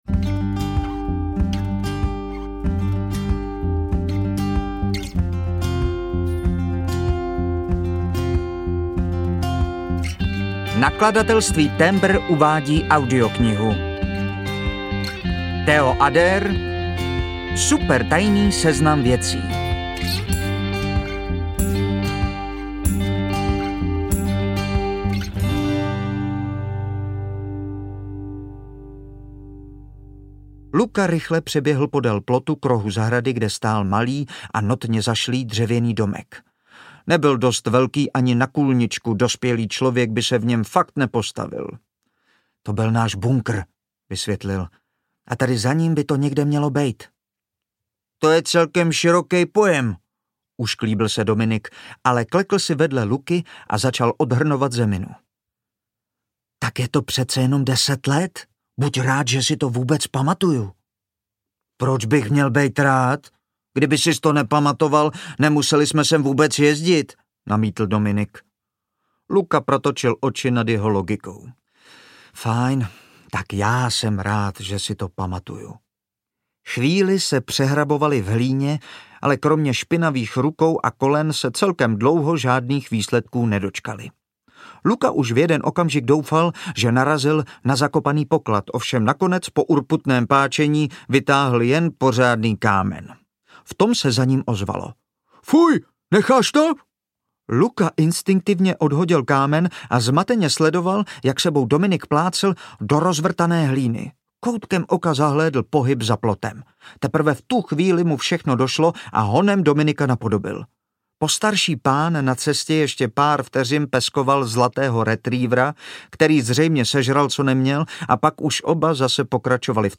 Supertajný seznam věcí audiokniha
Ukázka z knihy